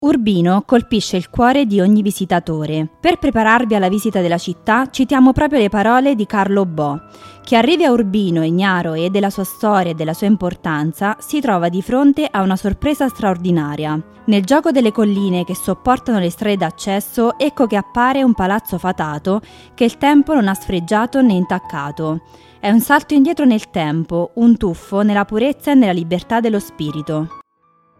AUDIOGUIDA E SISTEMA MULTILINGUE